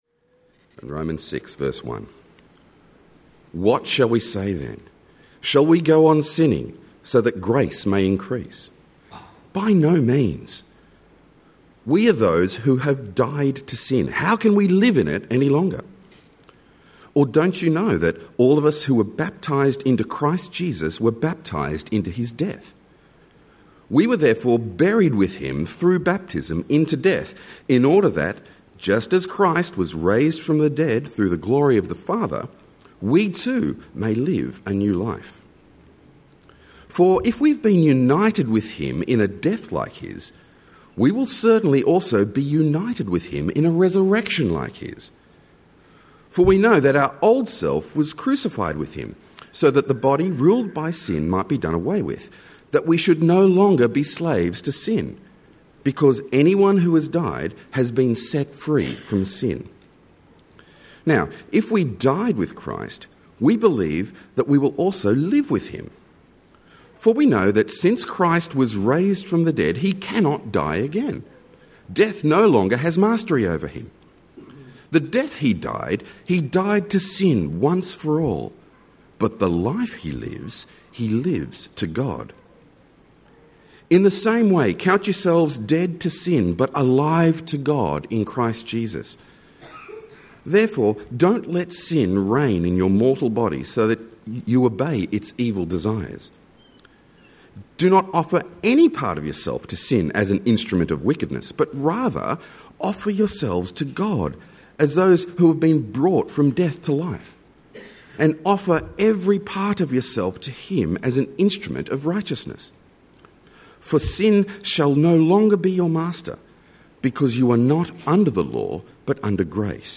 Sermon – Whose Slave are You? (Romans 6:1-7:6)